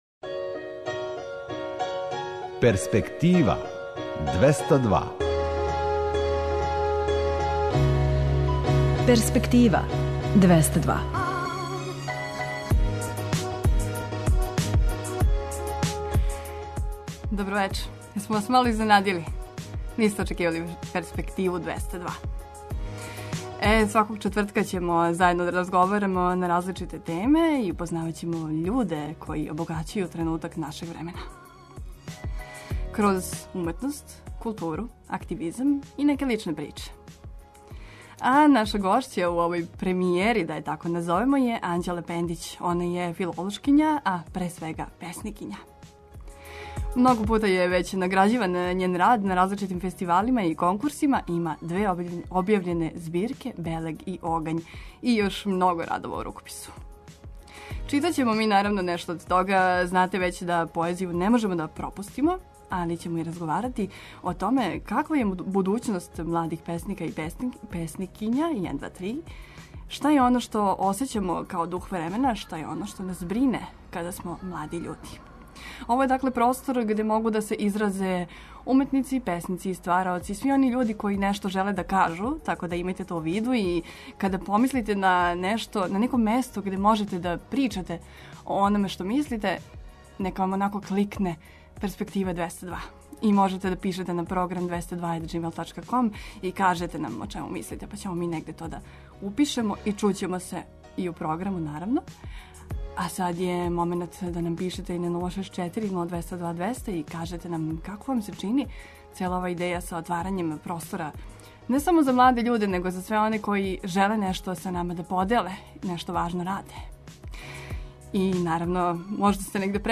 Читаћемо поезију и разговарати о томе каква је будућност младих песника и песникиња у нашој земљи, шта осећамо као „дух времена” који нас окружује, на које начине, као млади људи, можемо да донесемо добре промене.